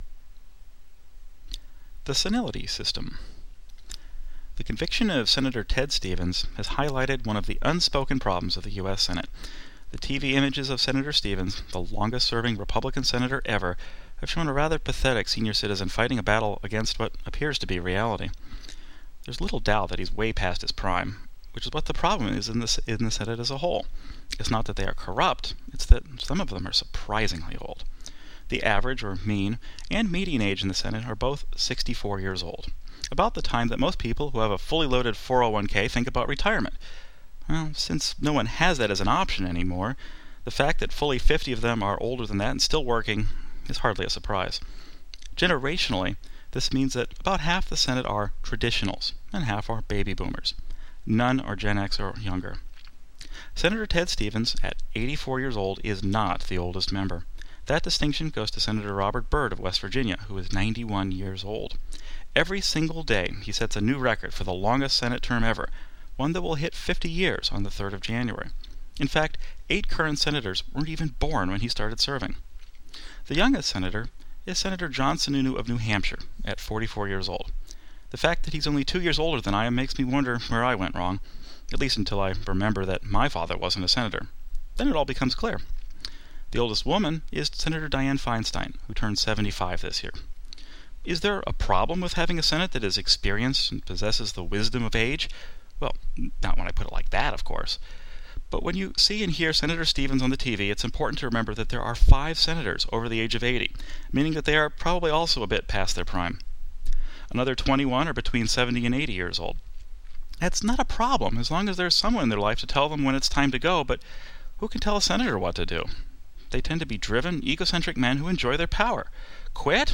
Click the “play” arrow below to hear me read this work.